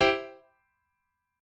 admin-leaf-alice-in-misanthrope/piano34_9_005.ogg at main